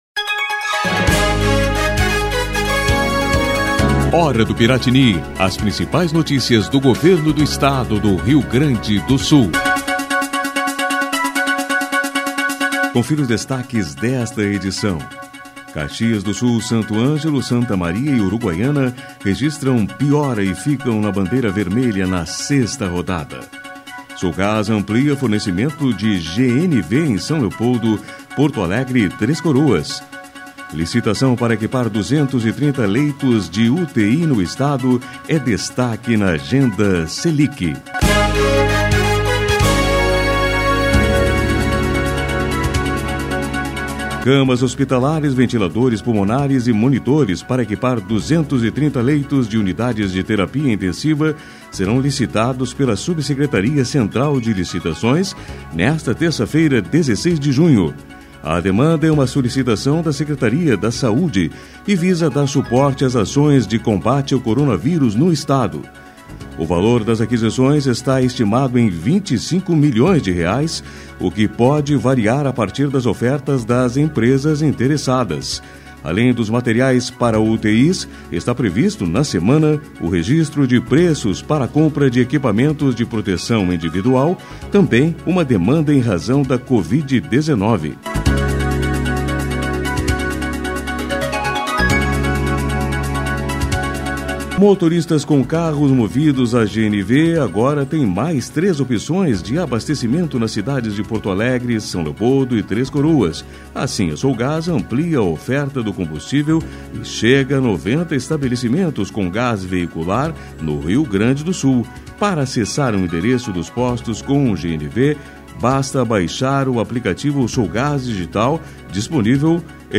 A Hora do Piratini é uma síntese de notícias do Governo do Estado, produzida pela Secretaria de Comunicação.